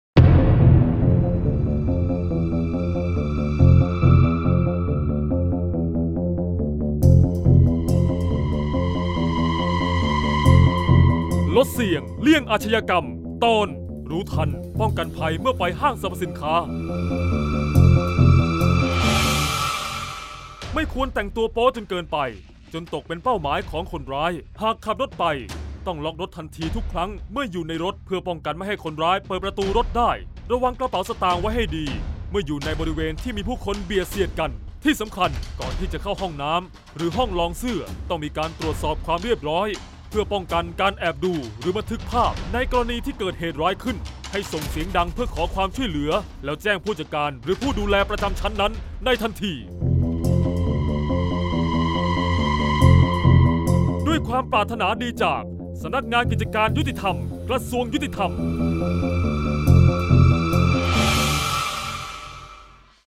เสียงบรรยาย ลดเสี่ยงเลี่ยงอาชญากรรม 26-รู้ทันภัยเมื่อไปห้าง
ลักษณะของสื่อ :   คลิปเสียง, คลิปการเรียนรู้, กฎหมาย ระเบียบ